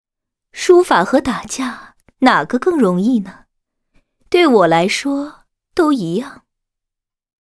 山海战记_苏轼_中文台词_12.mp3